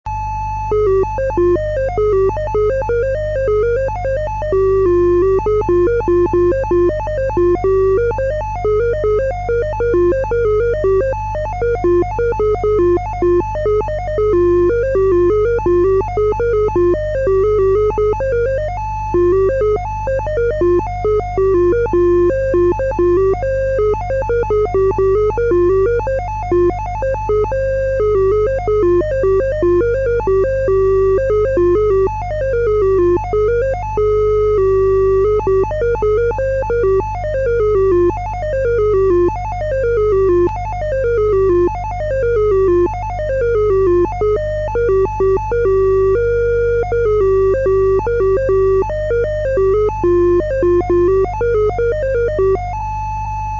Oltre a lavorare per il collaudo dello smistamento postale, PDP-8e amava
strimpellare.